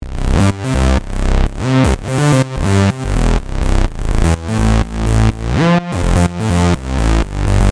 Pump Bassは、クラシックなサイドチェインダッキング（ポンピング）エフェクトを備えています。
▼Pump Bassサウンド
LogicPro12_PumpBass.mp3